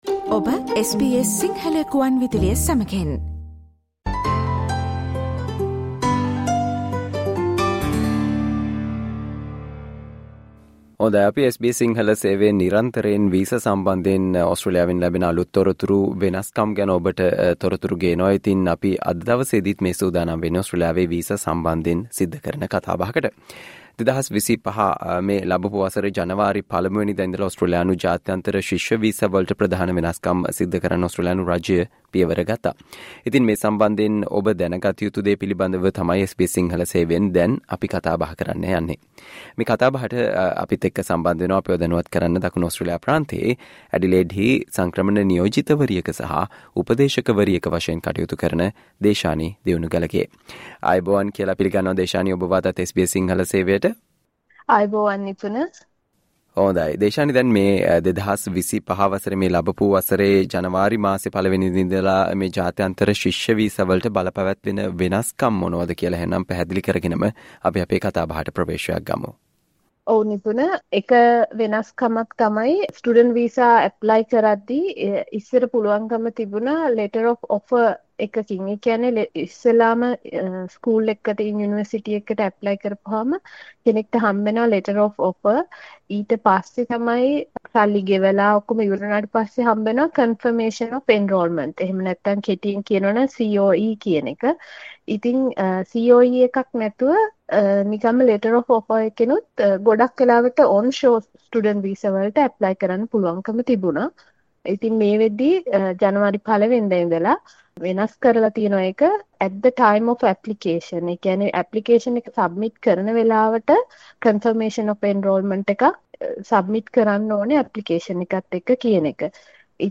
SBS Sinhala discussion on changes to onshore student visa applications.